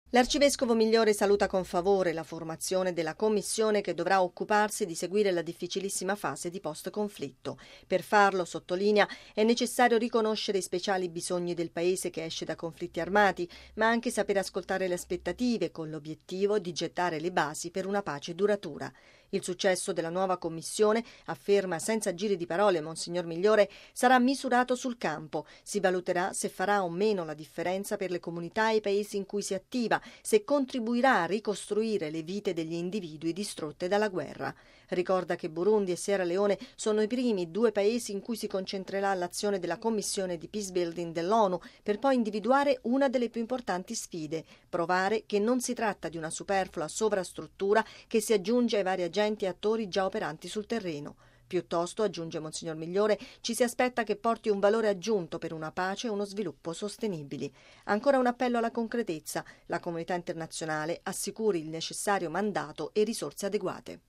◊   “Fare la differenza nella vita dei popoli e dei Paesi”: è quello che l’osservatore permanente della Santa Sede alle Nazioni Unite, arcivescovo Celestino Migliore, chiede alla nuova Commissione di Peacebuilding dell’ONU, nel discorso tenuto ieri alla VI sessione dell’Assemblea Generale delle Nazioni Unite a New York.